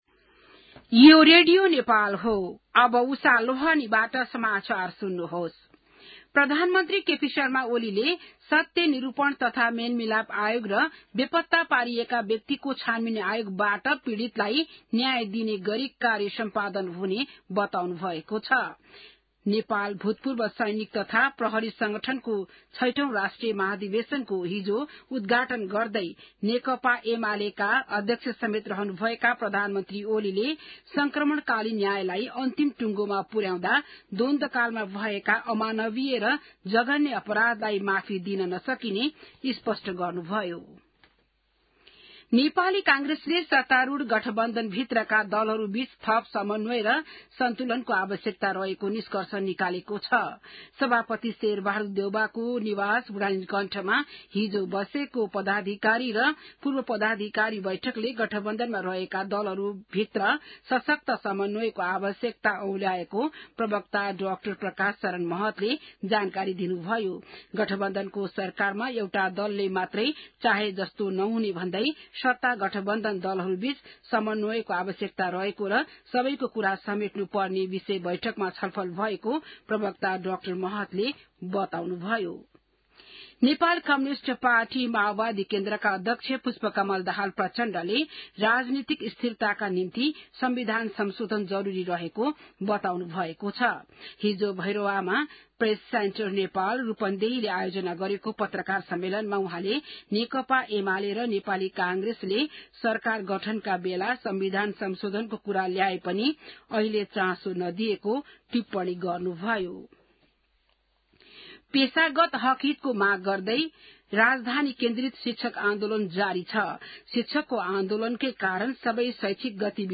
An online outlet of Nepal's national radio broadcaster
बिहान १० बजेको नेपाली समाचार : ५ वैशाख , २०८२